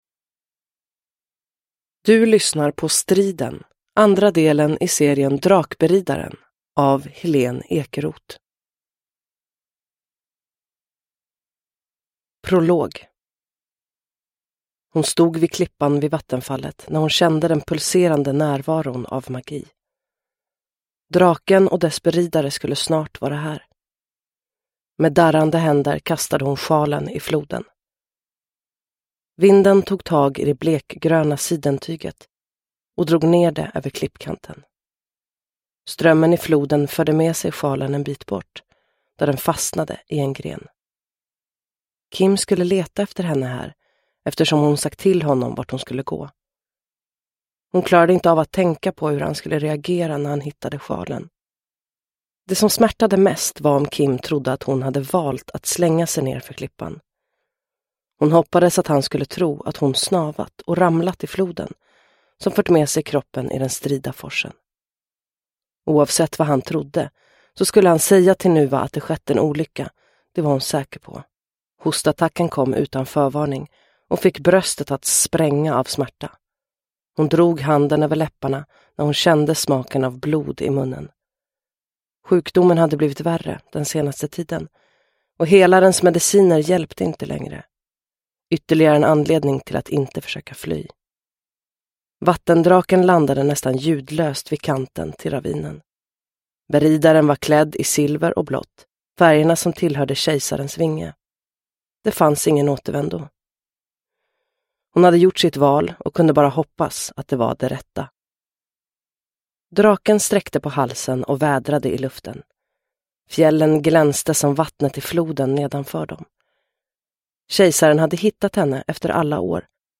Striden – Ljudbok